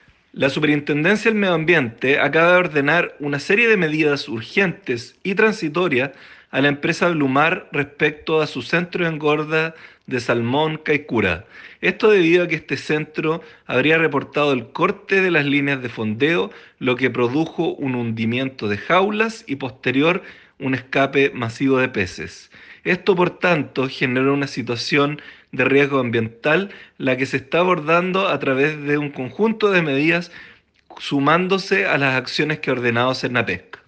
Al respecto, el Superintendente del Medio Ambiente, Cristóbal De La Maza señaló cuáles son los requerimientos que se realizan a los entes competentes.